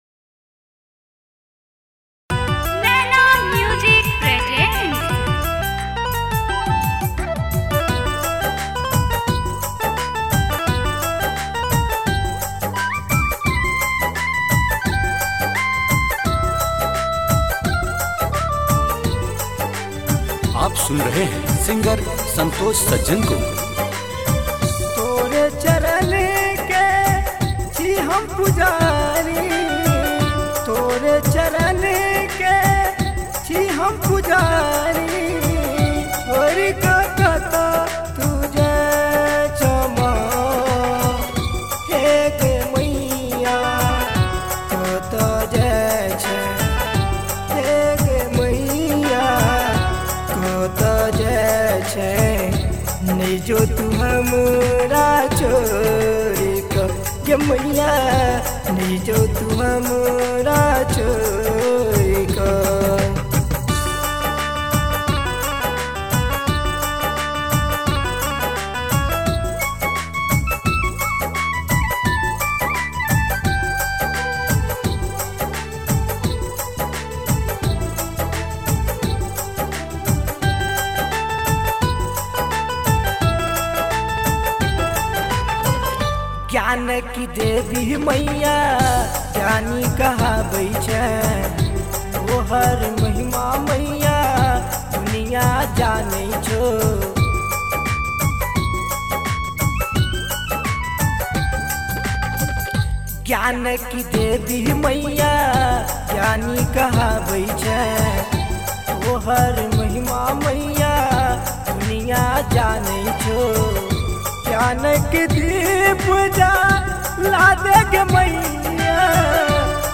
Maithili Saraswati Puja Geet